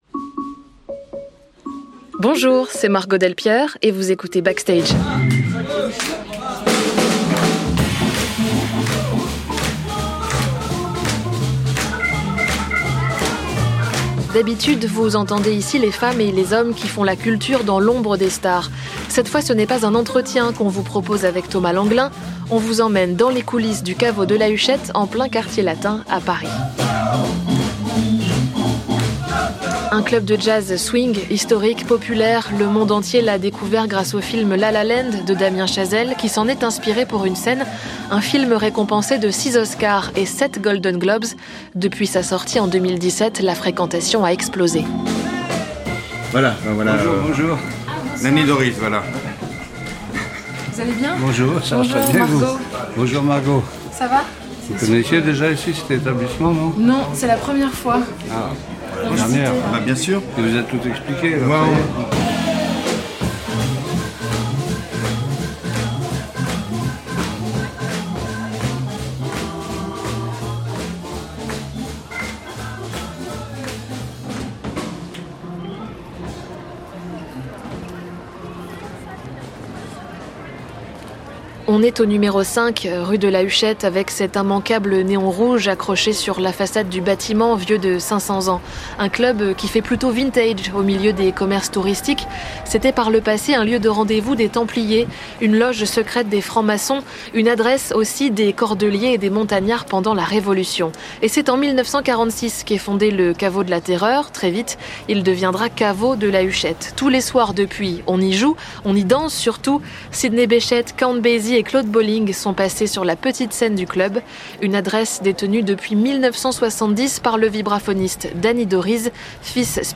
Ils nous font découvrir les coulisses de la création par la voix de ceux qui font la culture
Reportage enregistré le 17 septembre 2019,.